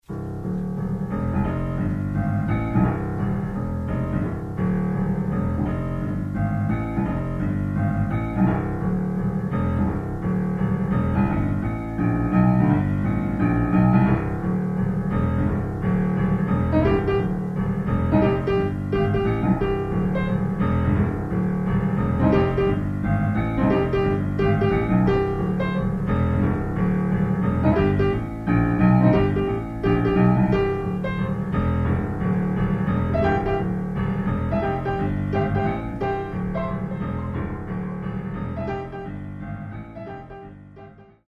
Style: Trad. Jazz Piano
weil einfachen aber swingenden Blues